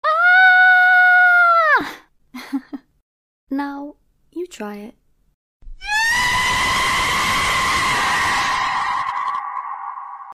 now you try it scream Meme Sound Effect
now you try it scream.mp3